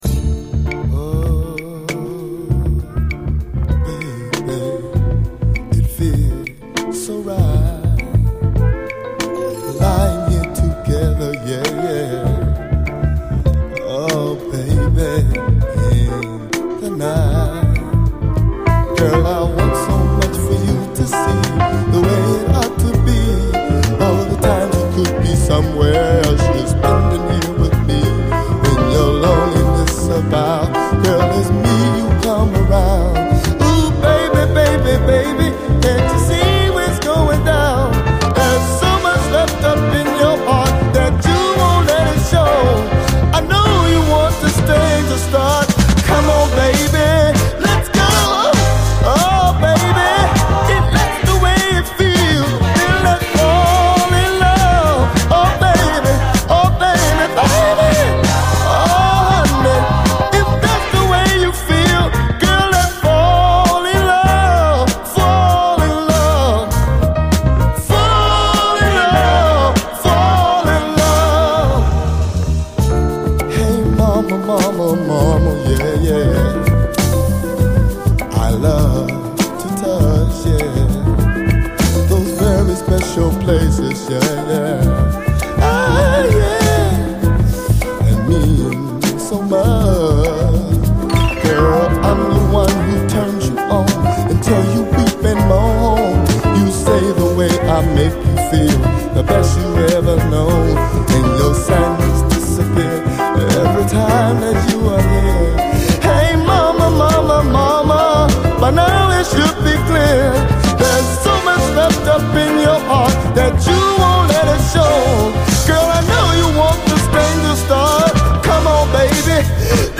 SOUL, 70's～ SOUL
細やかなアレンジが素晴らしい。